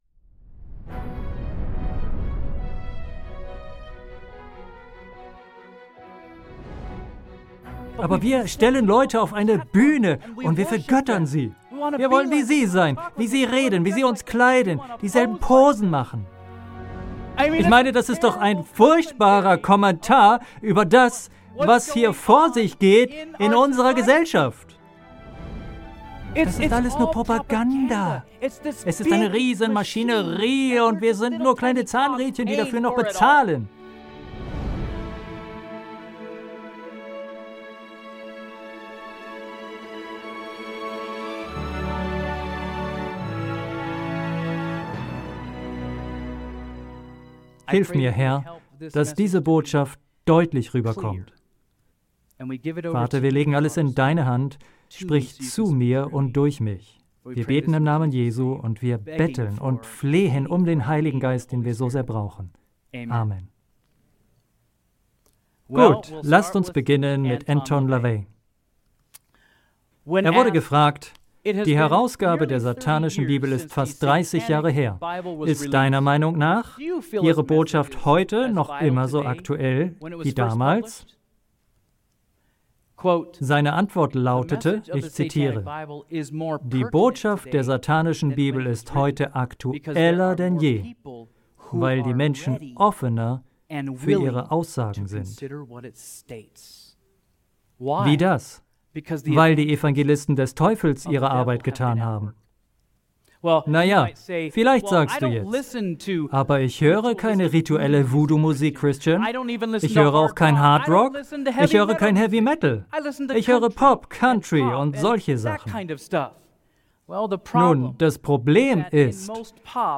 Dann bist du bei diesem Seminar genau richtig.